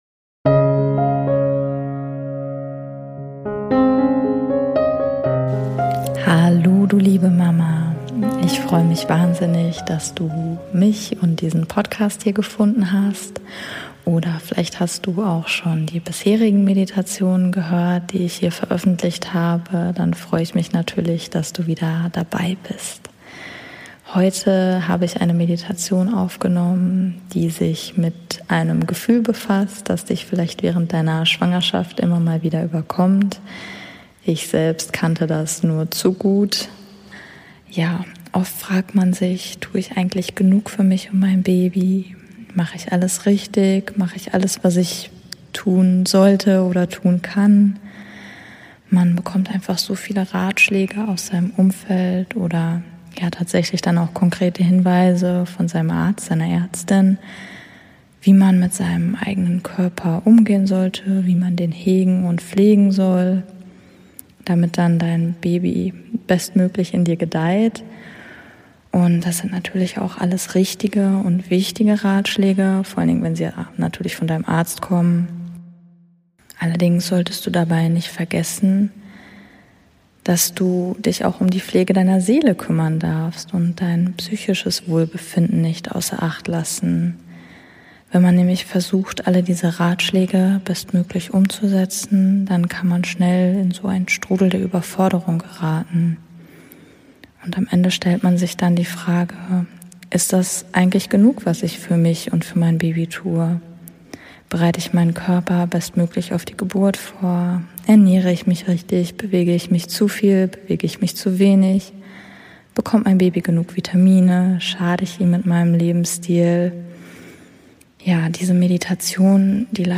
Diese Meditation leitet dich darin an, einmal in dein Inneres zu schauen und zurückkehren in dein Vertrauen, dass dein Körper genug ist.